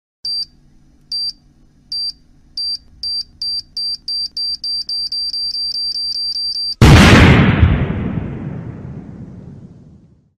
Звуки таймера бомбы
Таймер тикает с ускорением и в конце взрыв